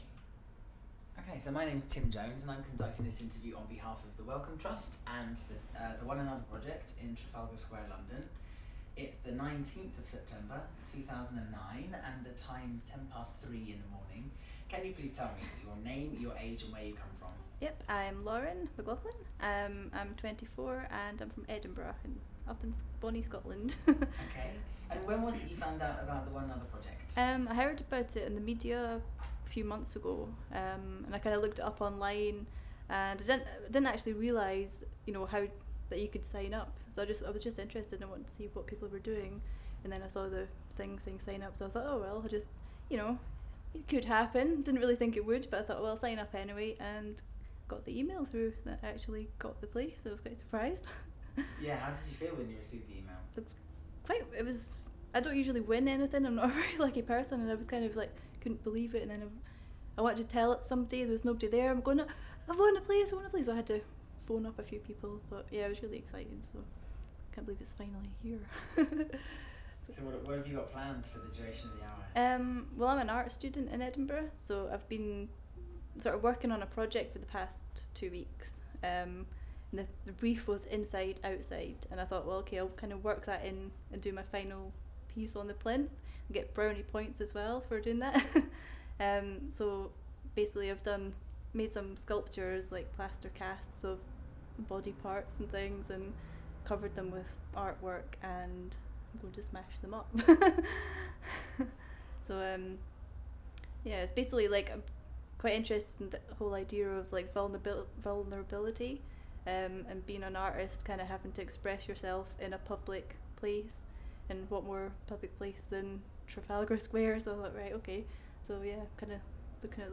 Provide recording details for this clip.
Audio file duration: 00:09:33 Format of original recording: wav 44.1 khz 16 bit ZOOM digital recorder.